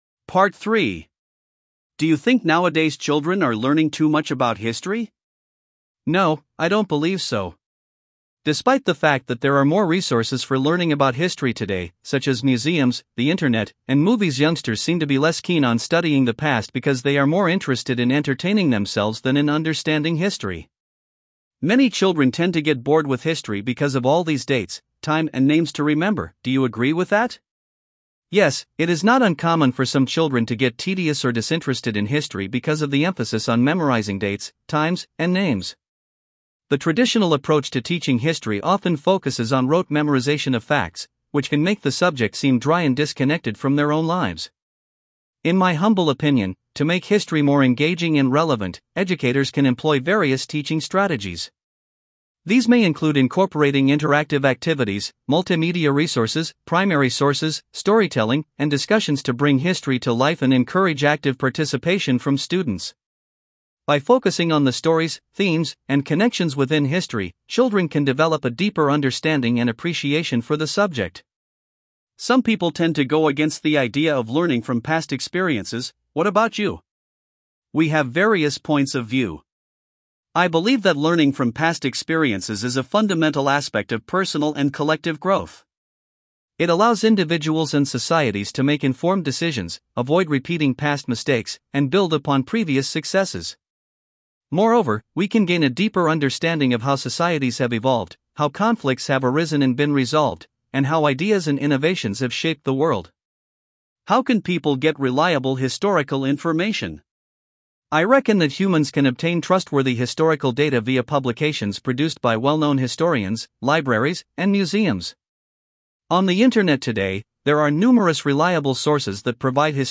Guy (English US)